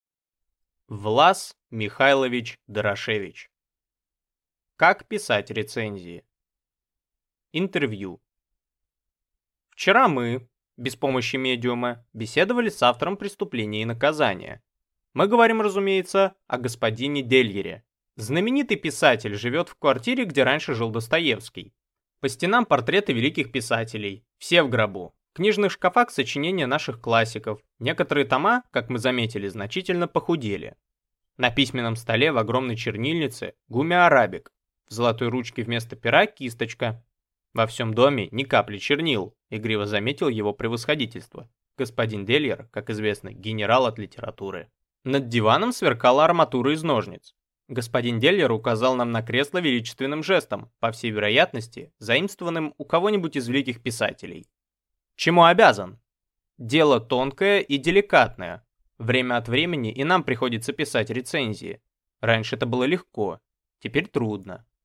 Аудиокнига Как писать рецензии | Библиотека аудиокниг